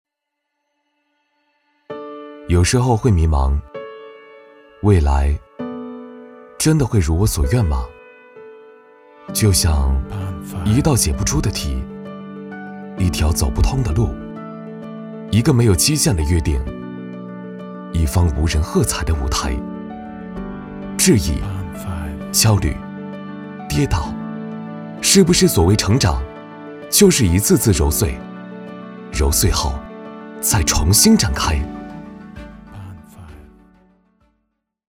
男145号-走心旁白配音-走心-保利tvc走心